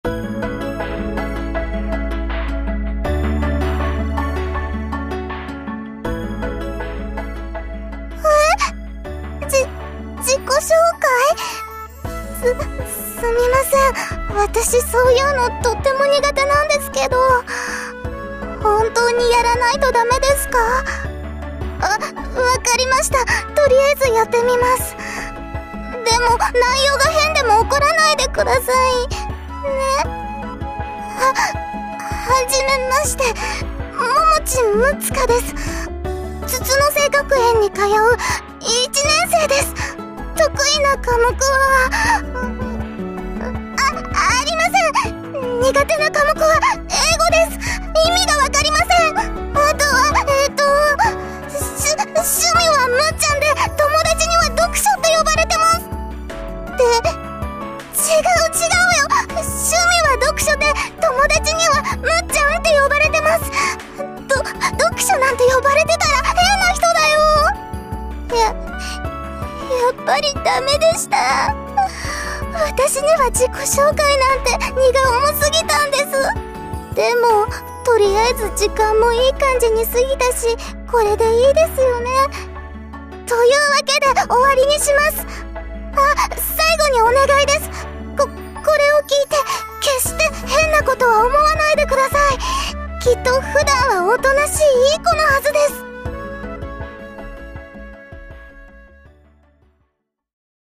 ドラマボイス 自己紹介編 PAGE UP